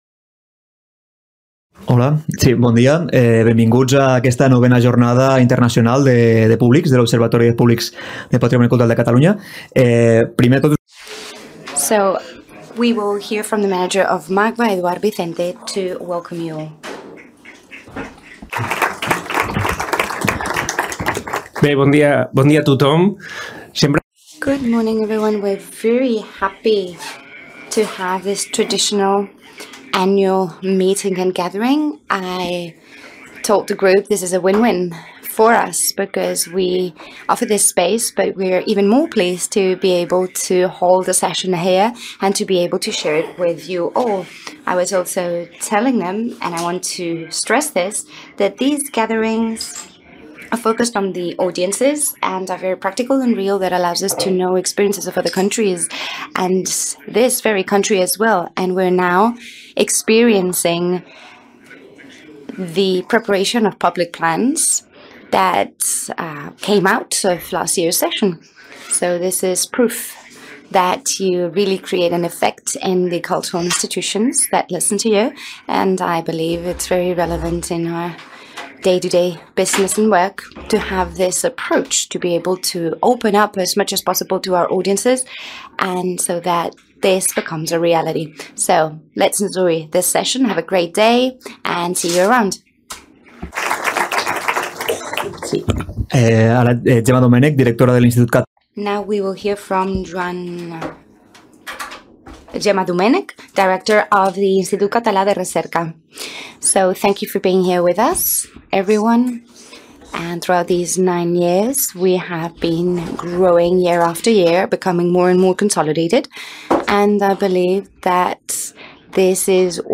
Acte de benvinguda i presentació de la 9a Jornada Observatori dels Públics del Patrimoni Cultural de Catalunya, dedicada als textos expositius centrats en els públics i a l'avaluació al servei de la transmissió de continguts.